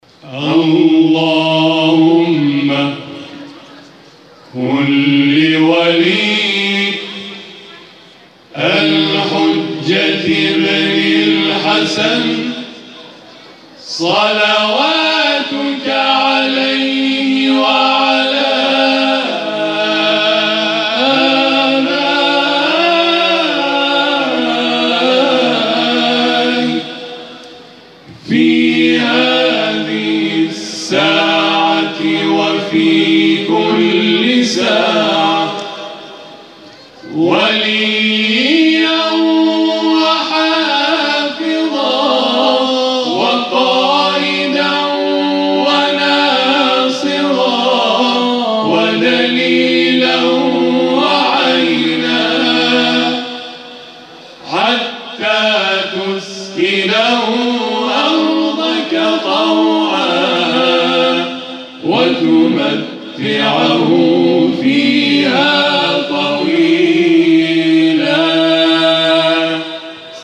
در پایان، اجرای شب گذشته این گروه تواشیح در محفل انس با قرآن کریم ویژه ماه مبارک رمضان در مجتمع فرهنگی سرچشمه ارائه می‌شود.
برچسب ها: گروه تواشیح ، جلسه قرآن ، محفل انس با قرآن ، گروه تواشیح نور